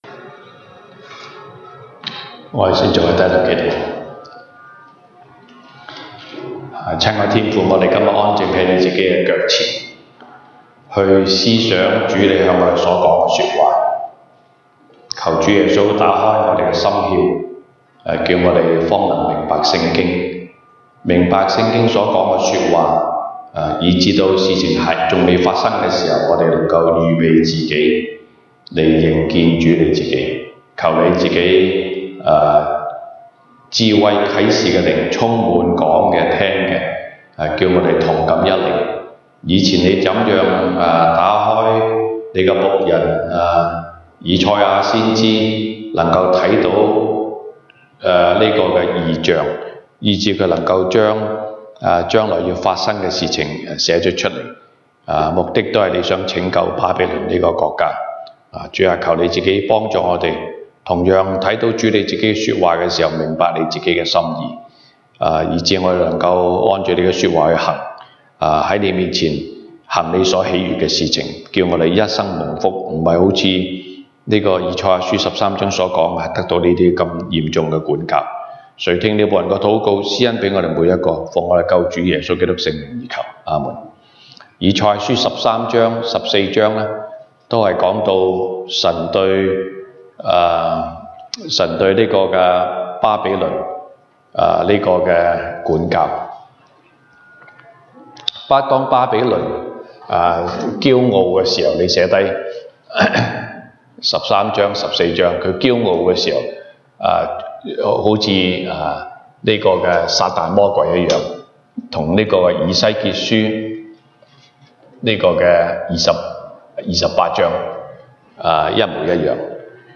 東北堂證道 (粵語) North Side: 敬拜那掌管未來的神